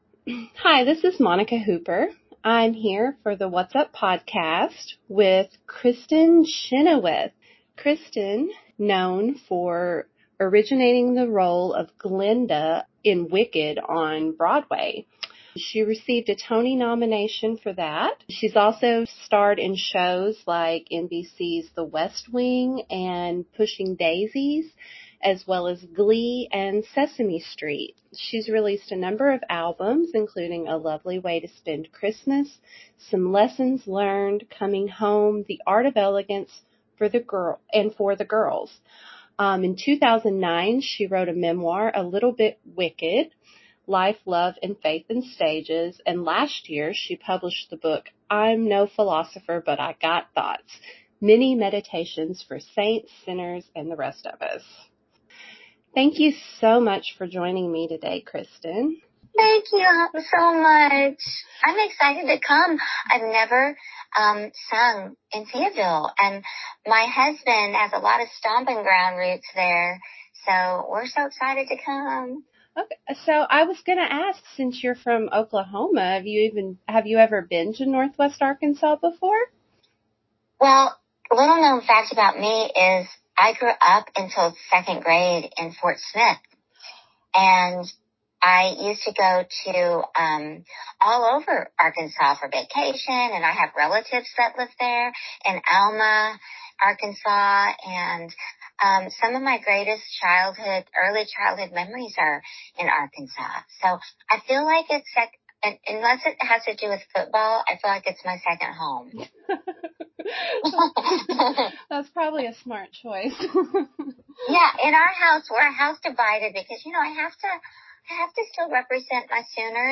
Interview with Kristin Chenoweth